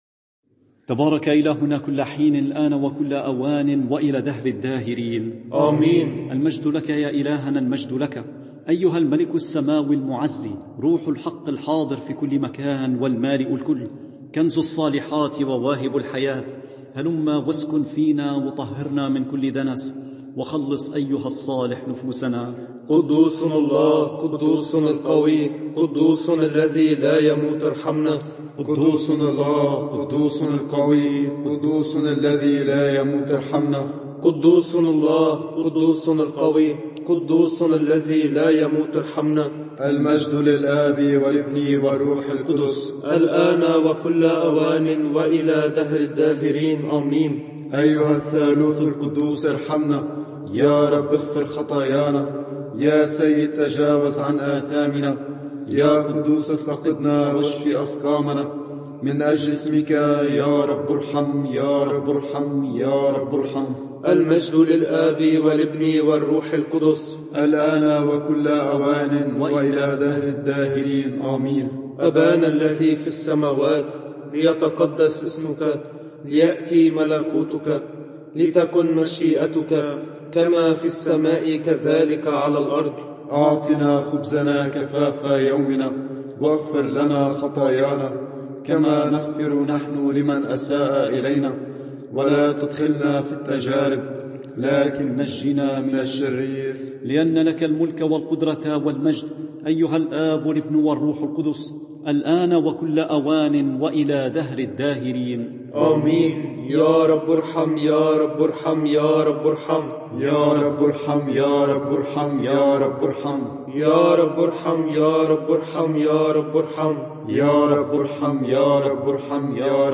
صلاة الساعة السادسة رهبان
صلاة الساعة السادسة رهبان.mp3